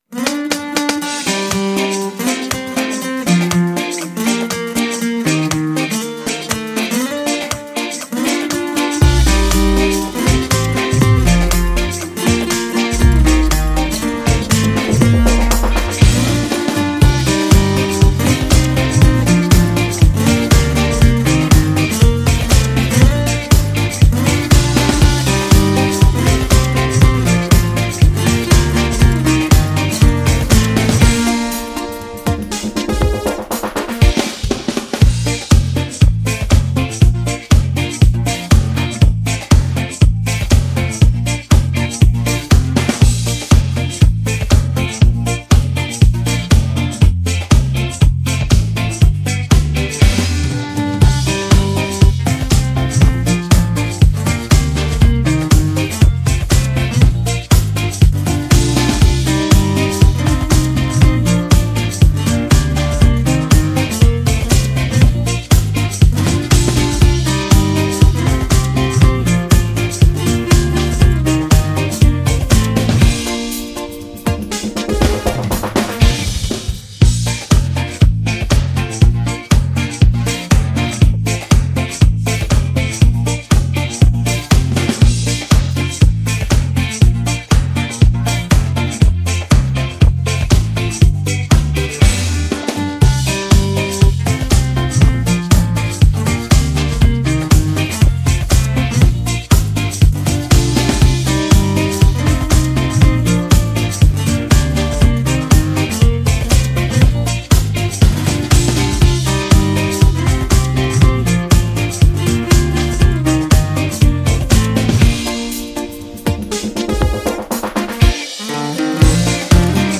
Детские песни